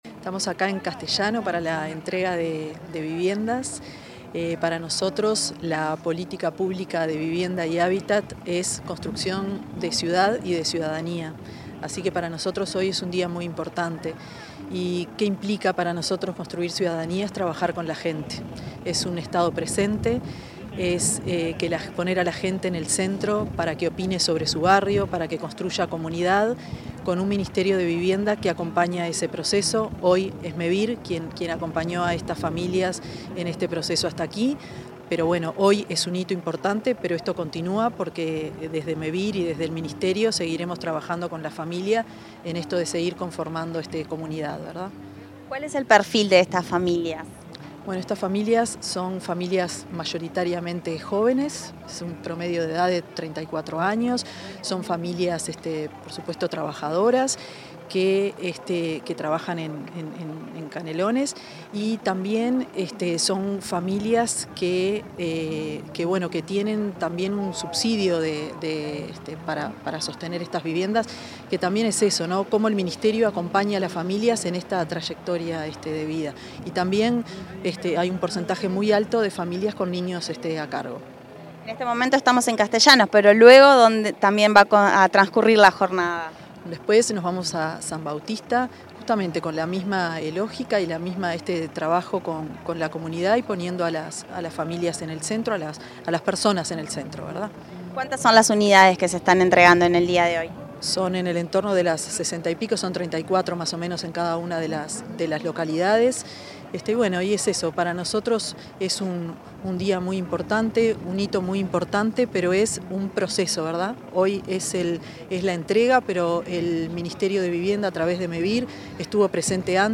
Declaraciones de la ministra Tamara Paseyro
Declaraciones de la ministra Tamara Paseyro 28/11/2025 Compartir Facebook X Copiar enlace WhatsApp LinkedIn En ocasión de la entrega de viviendas en la localidad de Castellanos, en Canelones, la ministra de Vivienda y Ordenamiento Territorial, Tamara Paseyro, realizó declaraciones a los medios periodísticos.